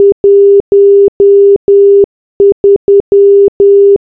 morse.wav